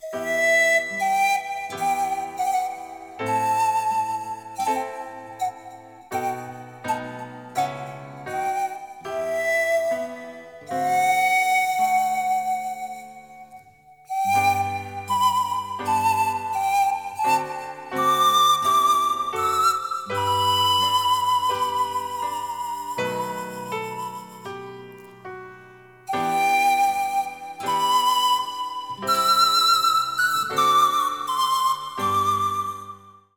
Notenliteratur mit 14 Melodien für Panflöte und Klavier